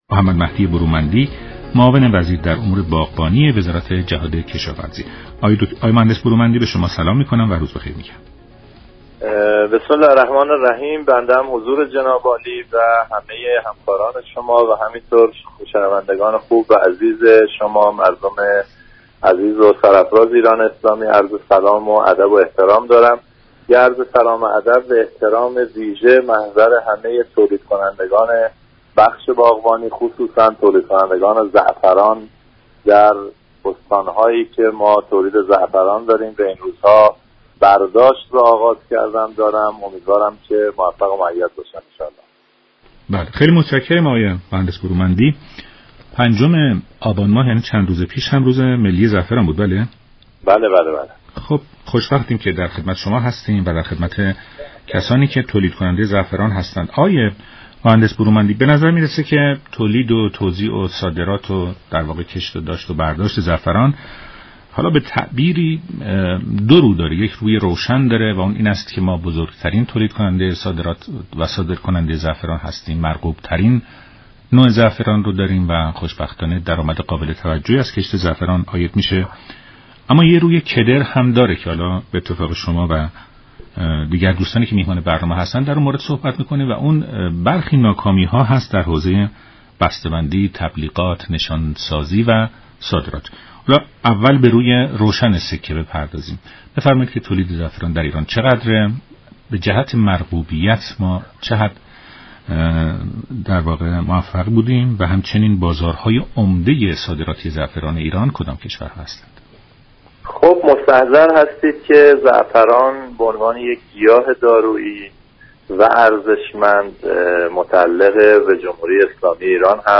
معاون در امور باغبانی وزات چهاد كشاورزی در برنامه ایران امروز گفت: حدود 14 درصد از این محصول در خراسان جنوبی و 3 درصد در خراسان شمالی تولید می‌شود.